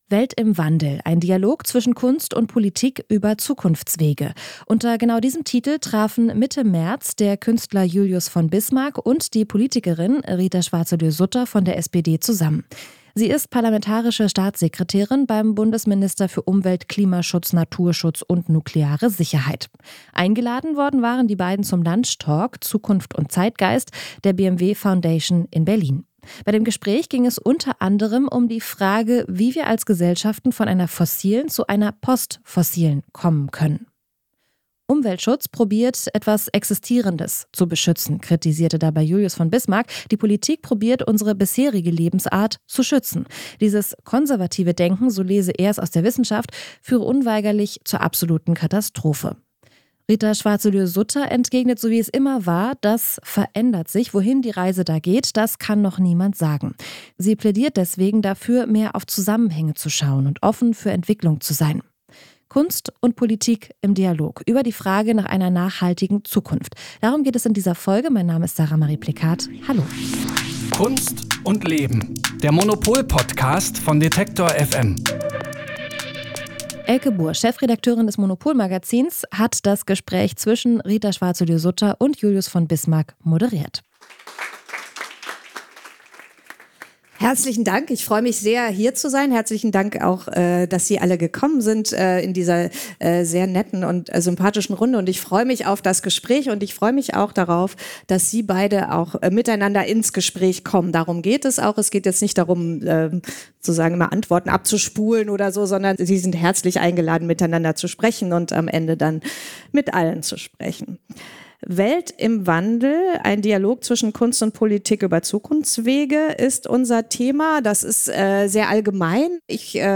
Wie sieht eine nachhaltige Zukunft aus? Der Künstler Julius von Bismarck und die Politikerin Rita Schwarzelühr-Sutter (SPD) im Gespräch.